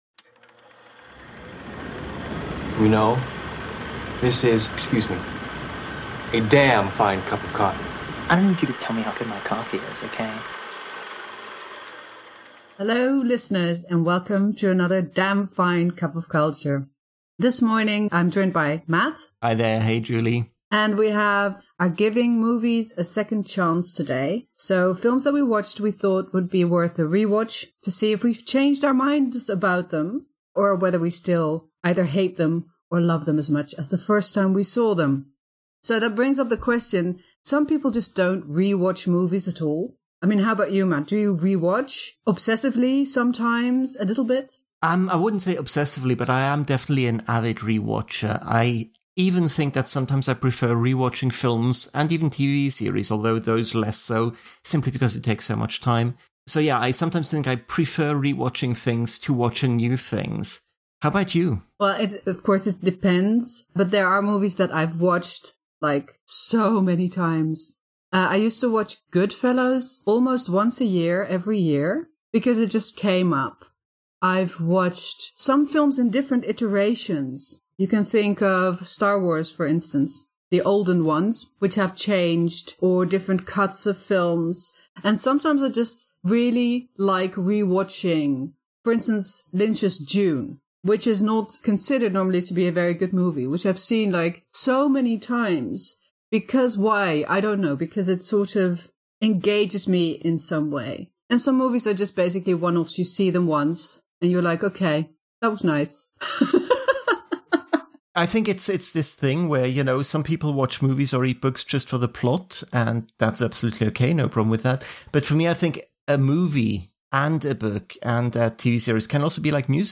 Join us for a conversation about expectations, being in the wrong mindset or mood, and what happens when you revisit a film ten, twenty years after you’ve first seen it.